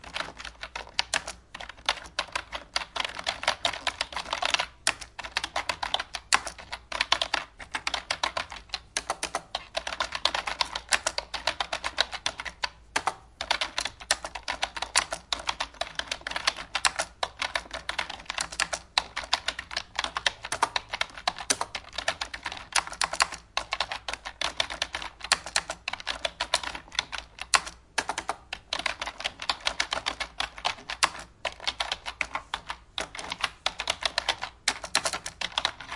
keyboardtypinglonglooping Gkz4uBVu
Tag: 键盘 打字 打字 键盘 电脑